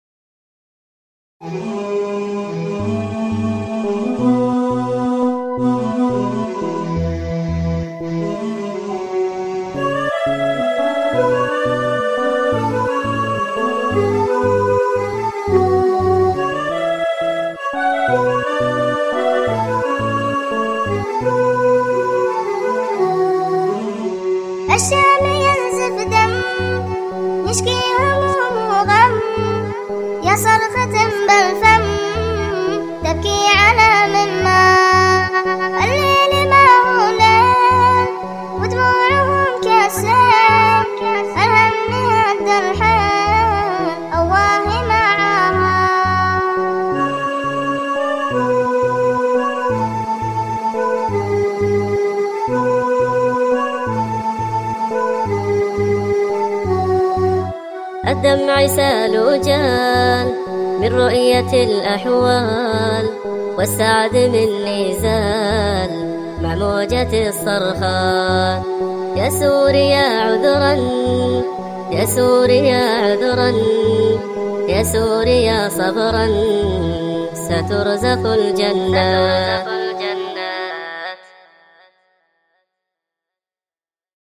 أنشودة
دويتو